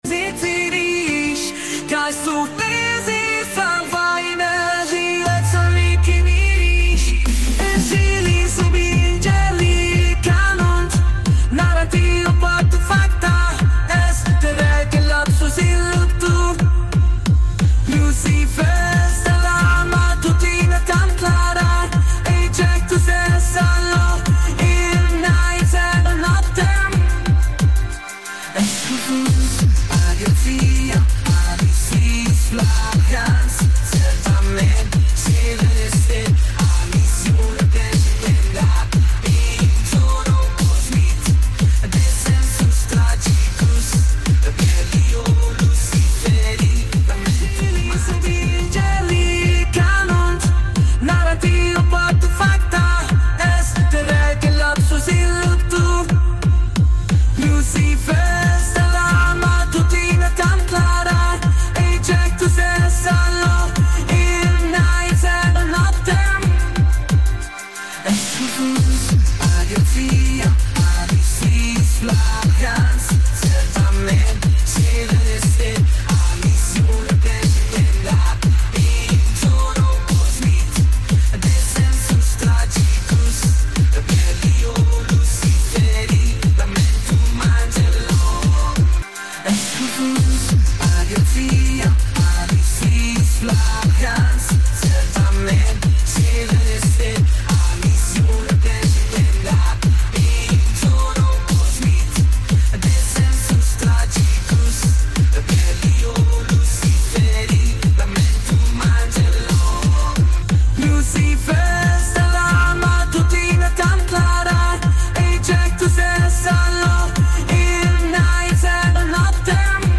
Gênero Funk.